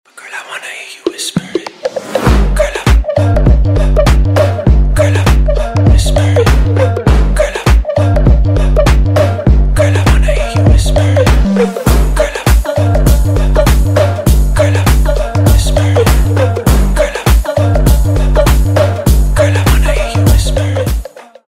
Клубные рингтоны
мужской голос